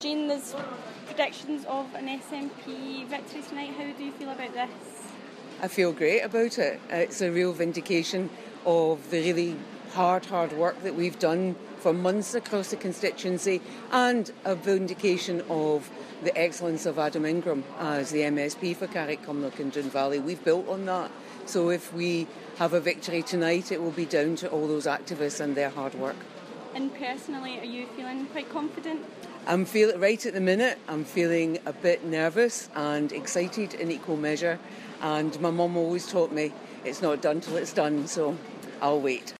Carrick Cumnock and Doon valley SNP candidate Jeanne Freeman speaks to our reporter